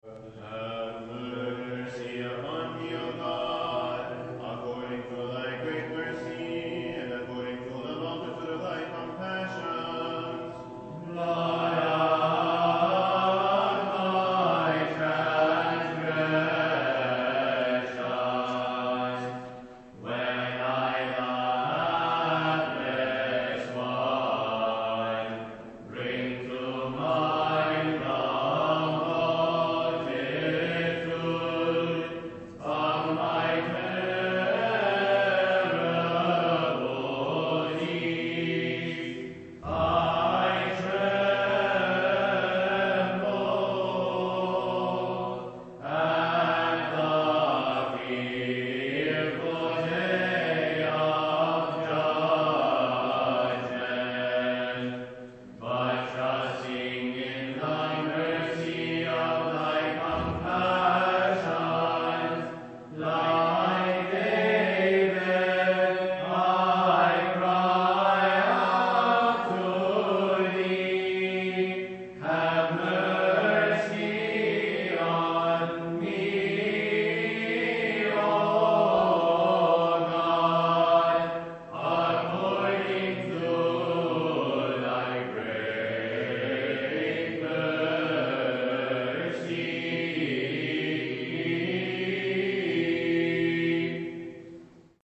Recordings of our Byzantine Choir
Live Recordings from Services